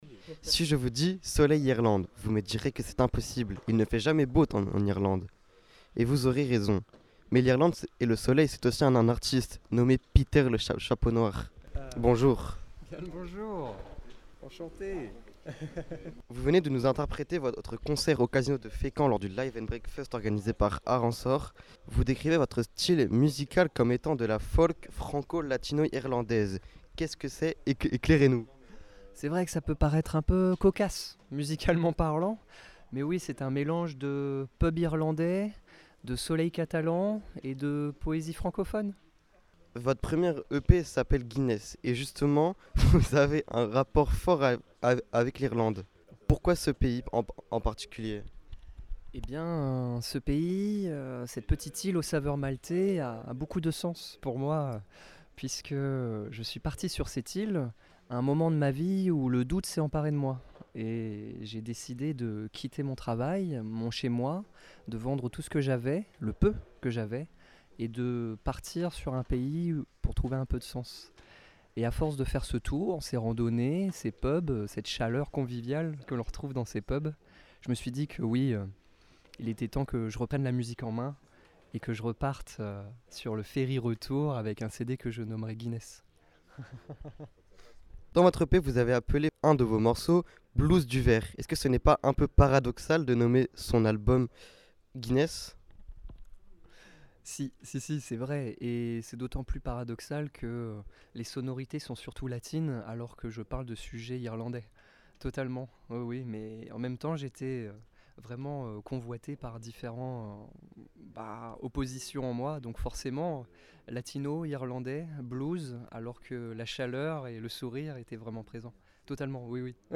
Un harmonica
Une guitare
Les interviews Radar Actu Interview fécamp podcast